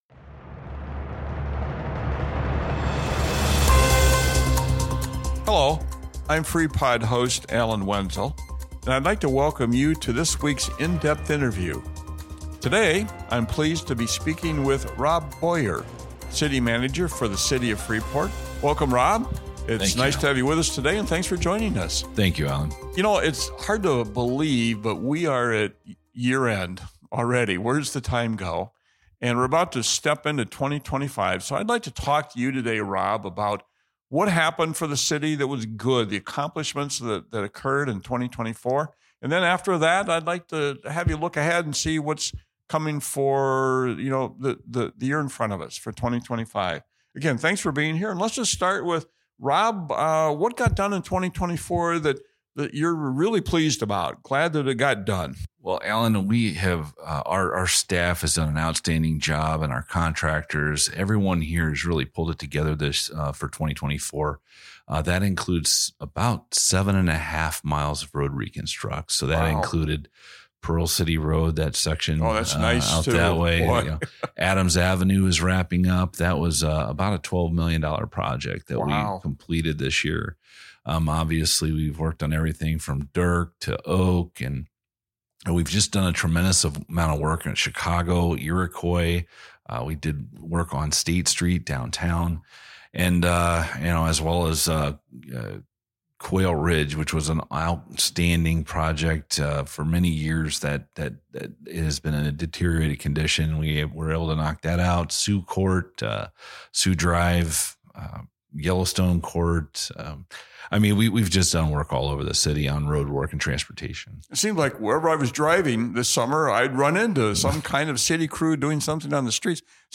Freepod - Freepod Interviews City Manager Rob Boyer